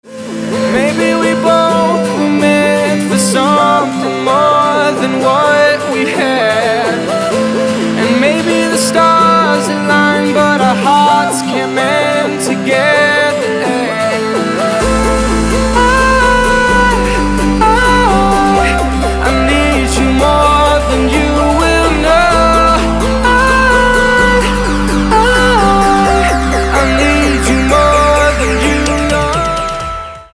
мужской вокал
progressive house
vocal